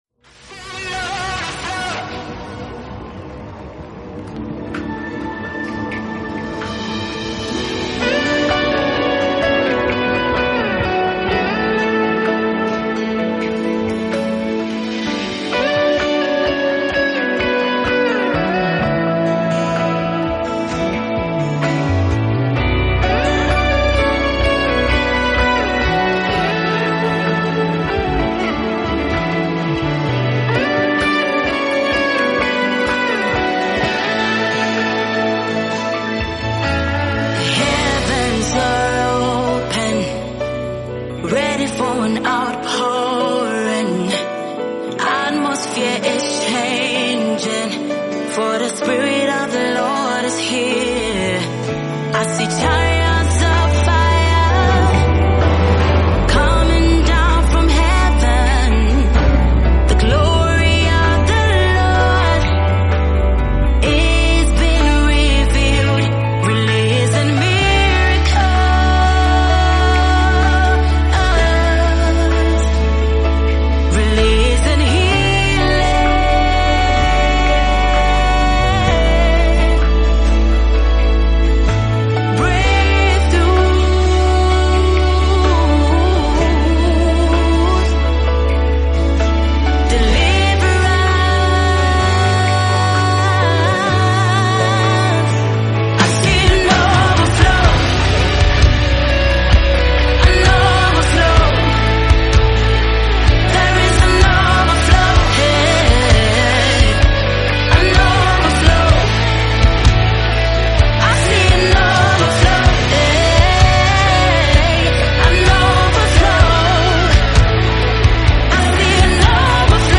contemporary gospel
atmospheric production
By blending relatable lyrics with a resonant soundscape